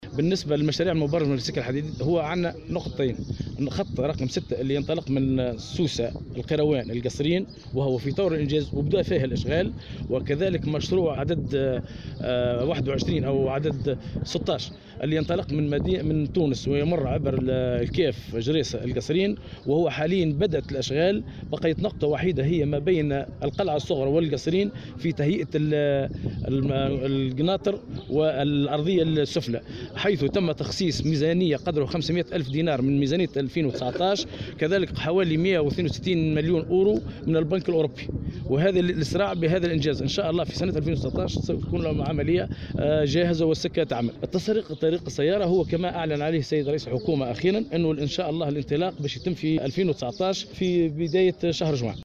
اشرف صباح اليوم 1 ماي2019 بمقر الولاية وزير التعليم العالي سليم خلبوس على جلسة الحوار الجهوي حول قطاع النقل تأتي الجلسة في إطار التحضير للحوار الوطني حول نفس القطاع الذي سيشرف عليه رئيس الحكومة يوسف الشاهد.